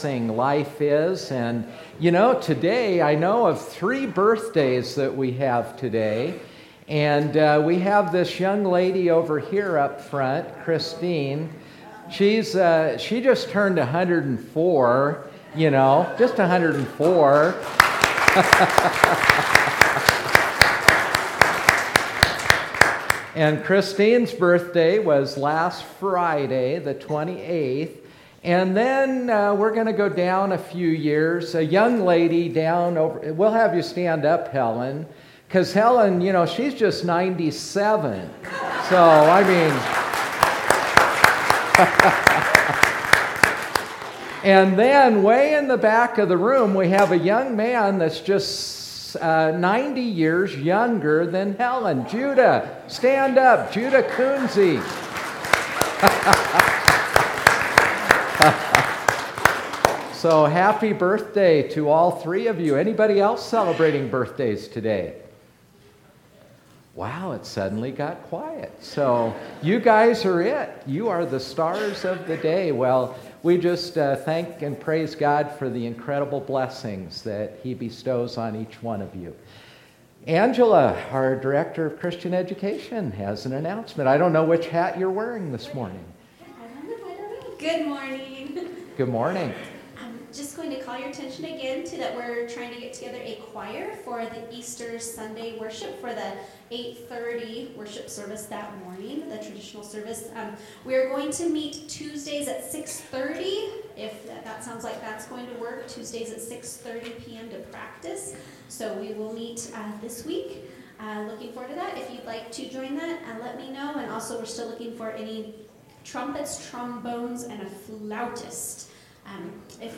Traditional Worship 3/1